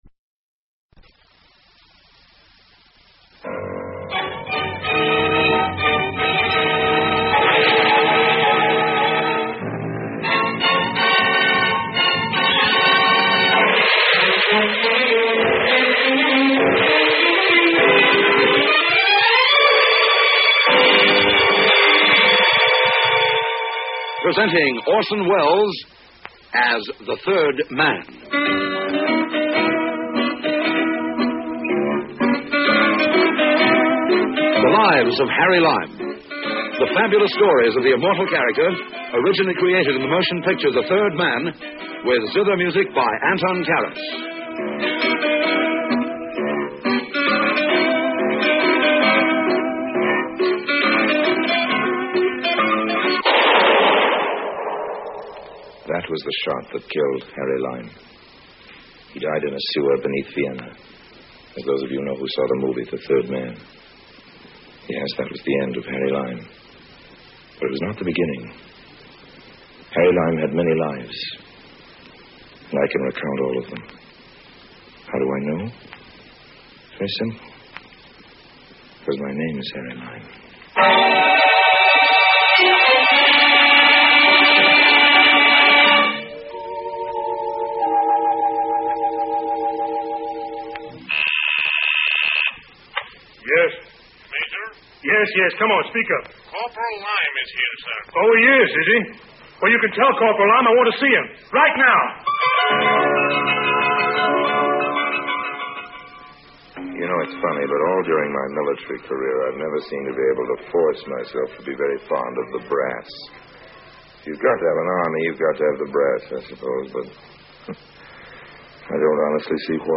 The Adventures of Harry Lime is an old-time radio programme produced in the United Kingdom during the 1951 to 1952 season. Orson Welles reprises his role of Harry Lime from the celebrated 1949 film The Third Man. The radio series is a prequel to the film, and depicts the many misadventures of con-artist Lime in a somewhat lighter tone than that of the film.